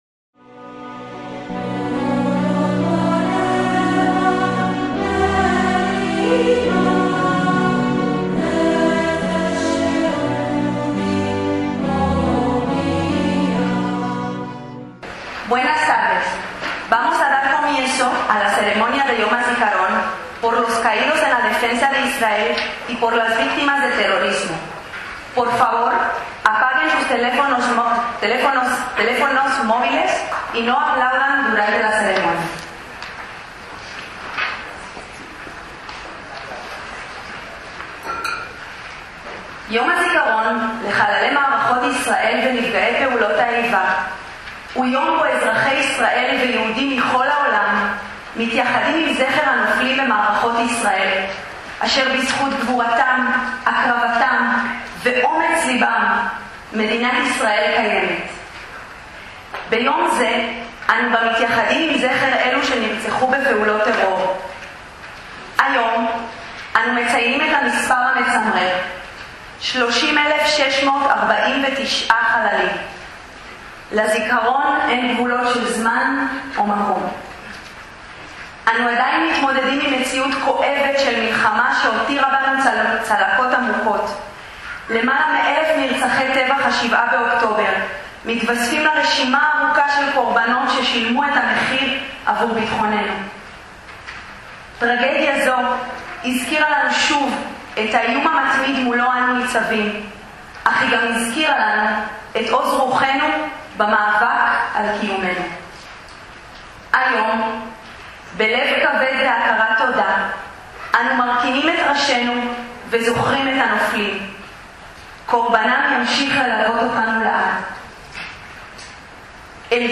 ACTOS EN DIRECTO - El pasado 29 de abril de 2025, después del rezo vestpertino al inicio del Yom Hazikaron (el día que Israel dedica a sus caídos en guerra y actos terroristas) tuvo lugar un acto de homenaje (en hebreo con traducción al español) organizado por la Embajada de Israel en España en los salones de la Comunidad Judía de Madrid, en el que intervinieron diferentes representantes de la delegación, así como de la comunidad judía local.